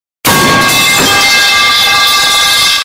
METAL PIPE
Metal_pipe.mp3